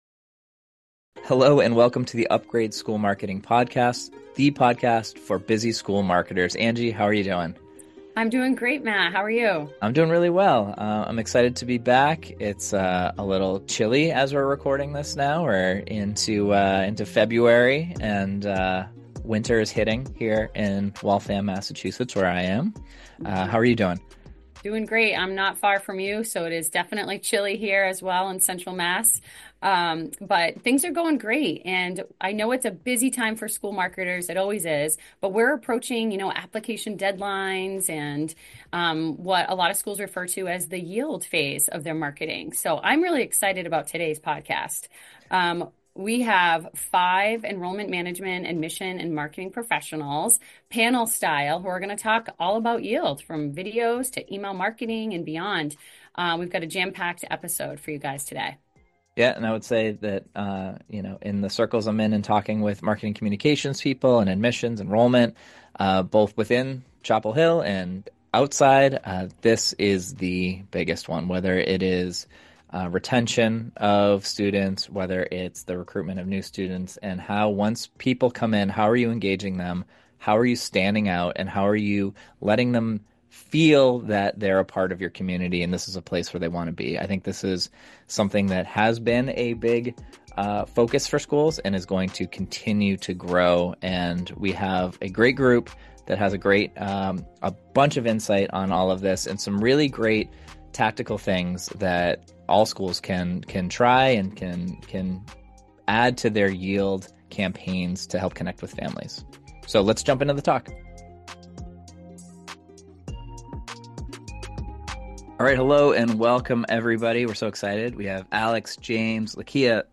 Five admissions experts share their most successful strategies for yield.
This panel-style episode comprises 5 Enrollment Management, Admission and Marketing Professionals from 5 different independent schools who are ready to talk yield. From clever acceptance packages and videos, to email marketing, ads, and beyond, this discussion is packed with creative ideas to help Independent Schools move parents and students from the acceptance stage to enrolled status.